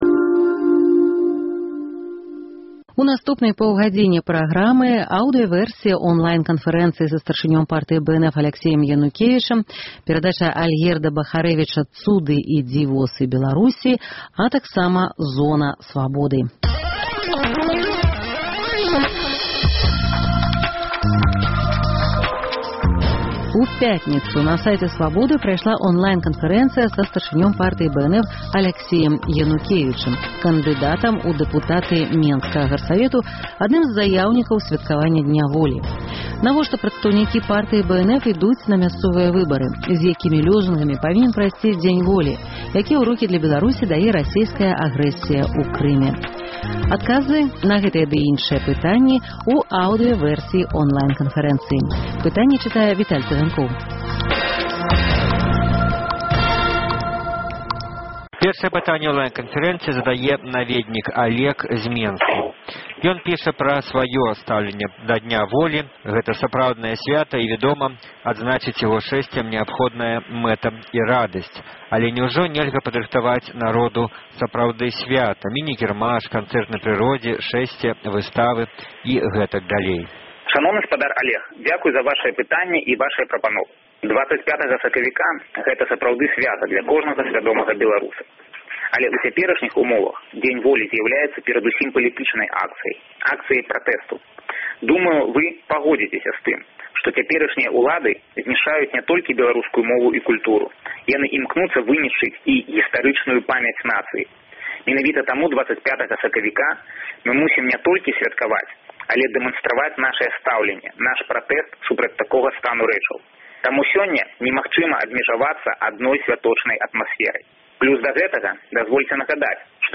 На вашы пытаньні падчас онлайн-канфэрэнцыі адказаў старшыня Партыі БНФ Аляксей Янукевіч, кандыдат у дэпутаты Менскага гарсавету па Галадзедаўскай выбарчай акрузе № 6.